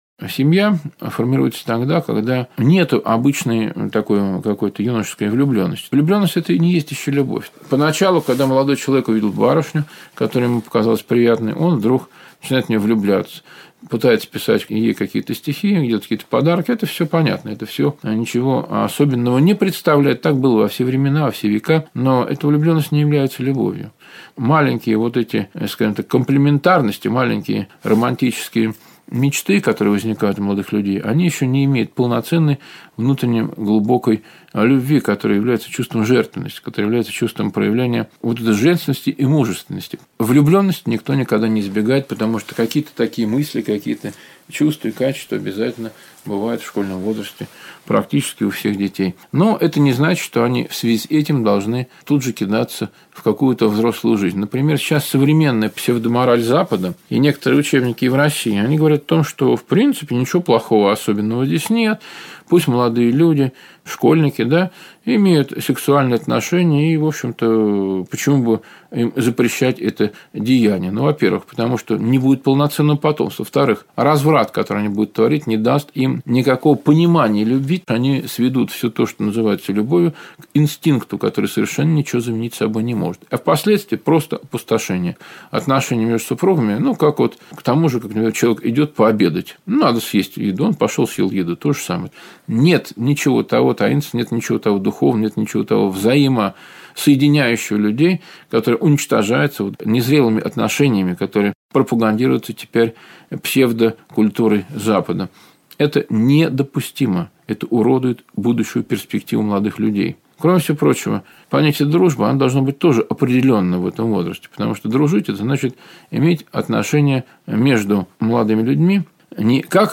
Заповедь Беседы Нравственность Брак Семья